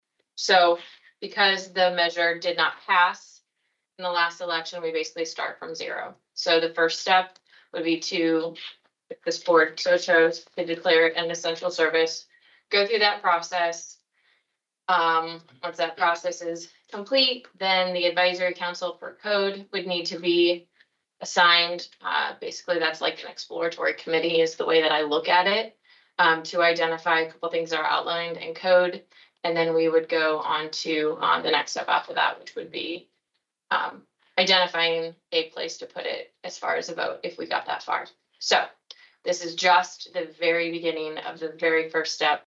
However, Board Chair Maggie Armstrong said at Tuesday’s meeting that the supervisors must declare it an essential service in order to address it moving forward